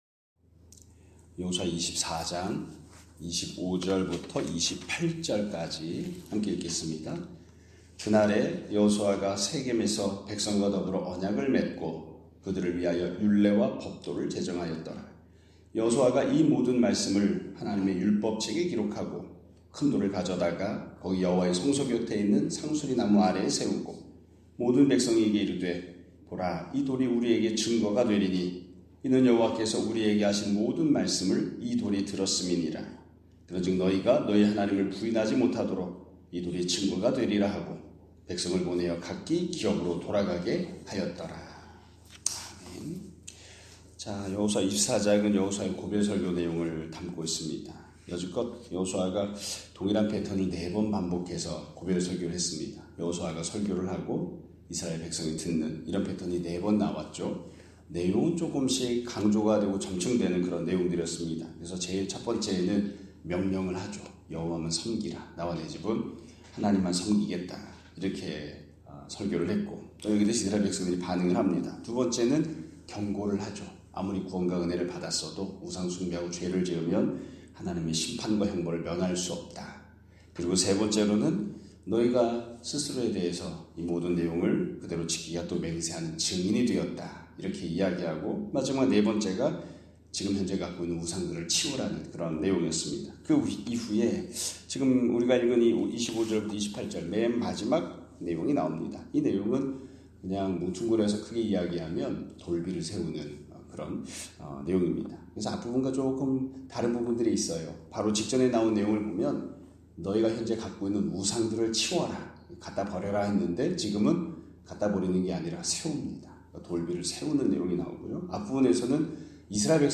2025년 3월 5일(수 요일) <아침예배> 설교입니다.